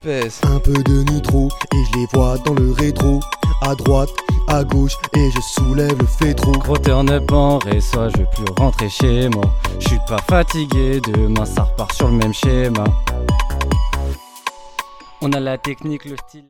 Avant le studio...